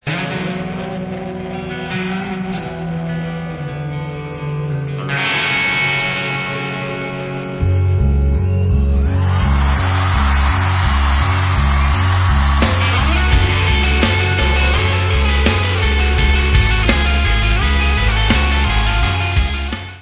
Live From Le Zenith